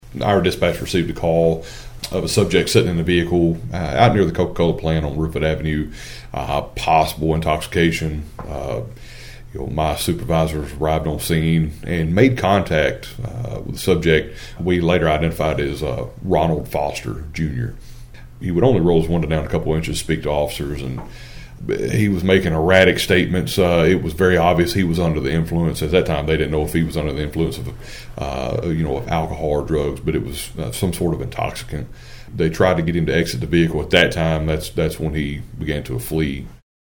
Union City Police Chief Ben Yates explained how the pursuit began.(AUDIO)